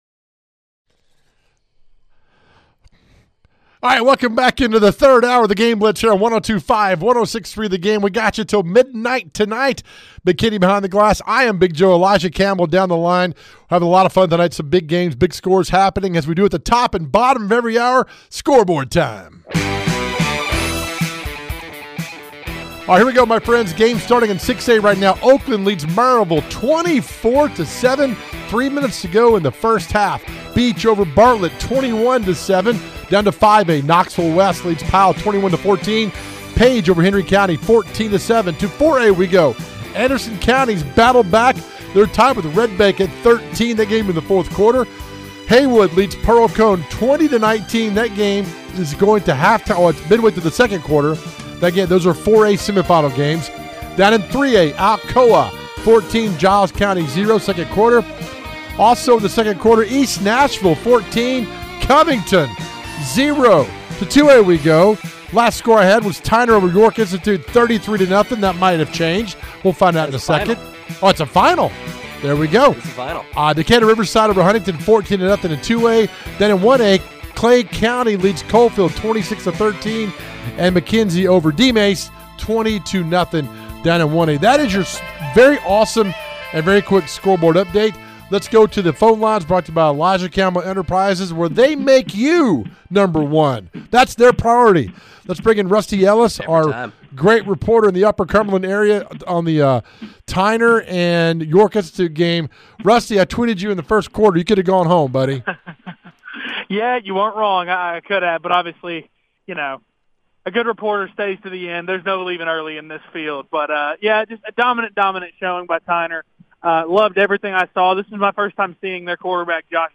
We are in hour 3 of our season finale covering a great season of Middle TN High School Football! The playoffs are in full swing, and the guys have full coverage right here with reporters on location and conversations with team coaches!